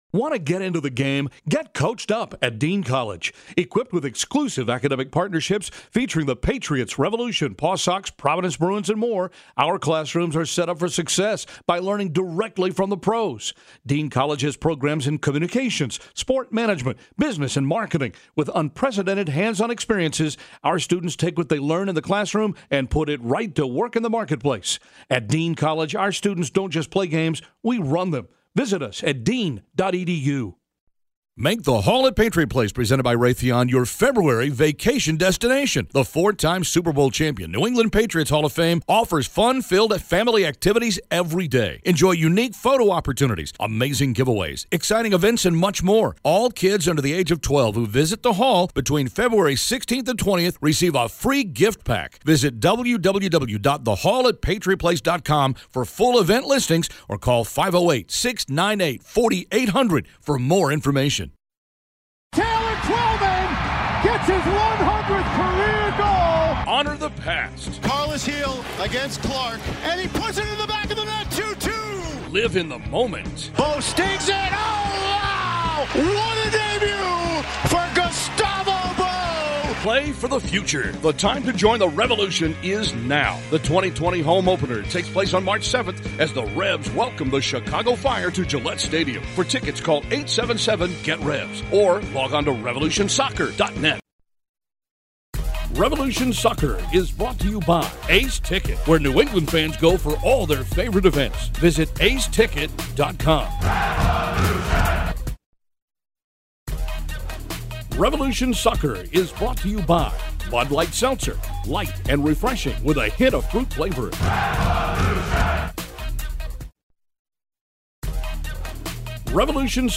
Providence College basketball clips